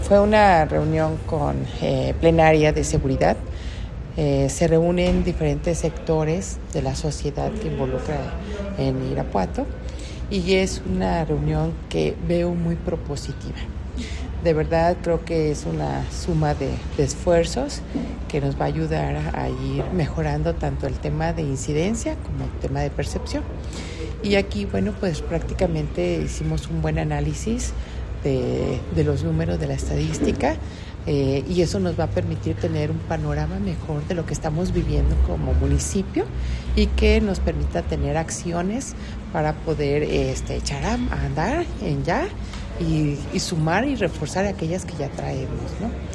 AudioBoletines
María del Consuelo Cruz Galindo, secretaria de seguridad